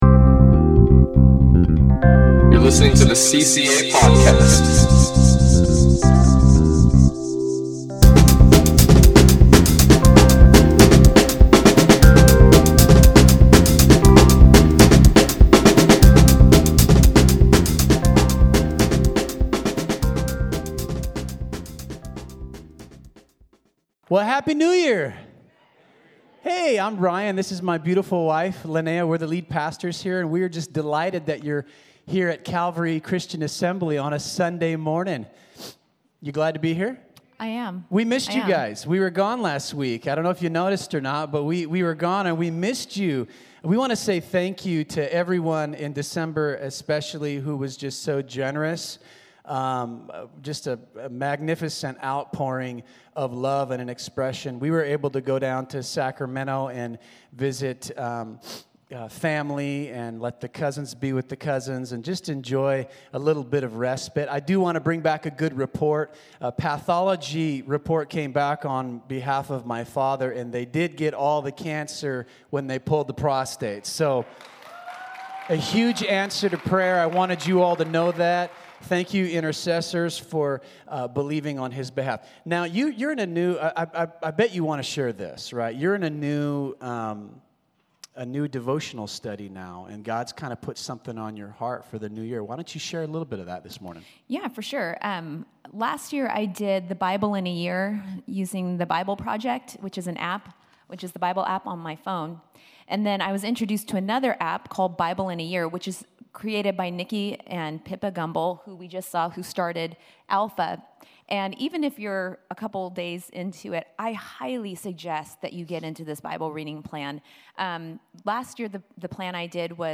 Listen to Message | Download Notes